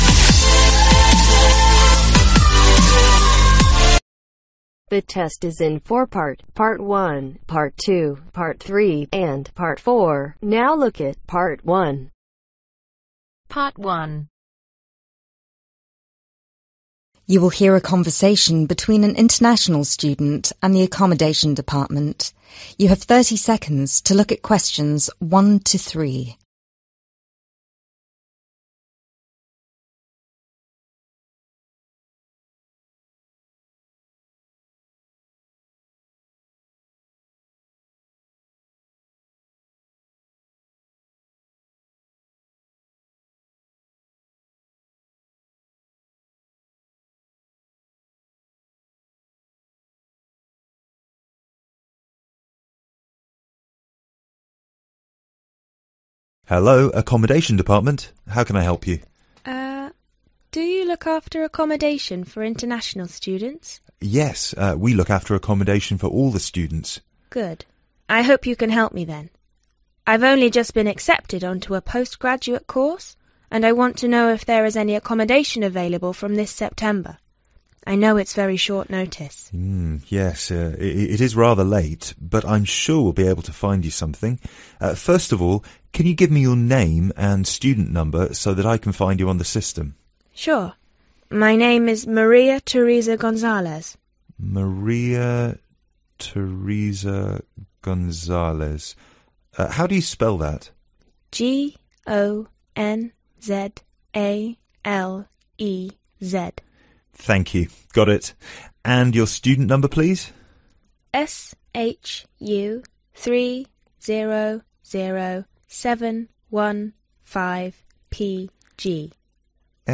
A parking garage B laundry room C en suite bathroom D kitchen E study room PART-2 Questions 11-14 Choose FOUR letters A – G. You are going to hear a radio interview with a self-publishing expert.